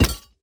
Minecraft Version Minecraft Version 1.21.5 Latest Release | Latest Snapshot 1.21.5 / assets / minecraft / sounds / block / vault / place2.ogg Compare With Compare With Latest Release | Latest Snapshot